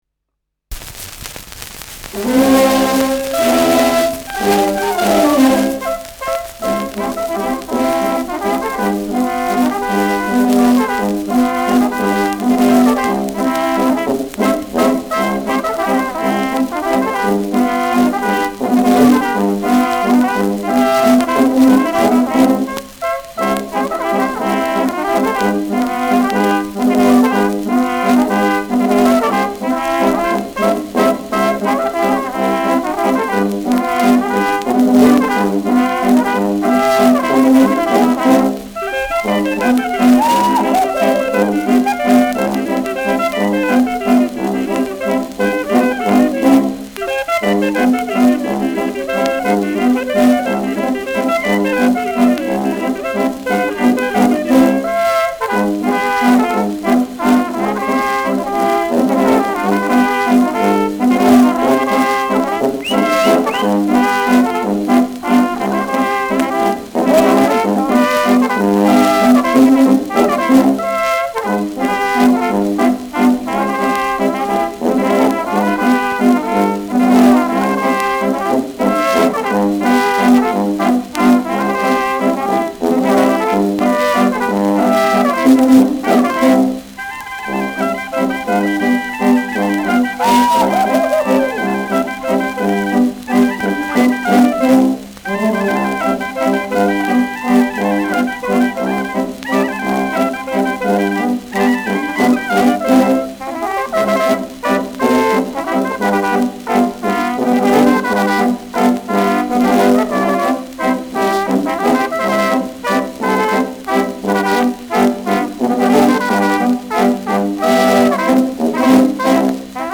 Schellackplatte
präsentes Rauschen : präsentes Knistern : abgespielt : leiert : vereinzeltes Knacken : gelegentliches „Schnarren“
Truderinger, Salzburg (Interpretation)
Juchzer, Pfiffe, Zwischenrufe.
[Salzburg] (Aufnahmeort)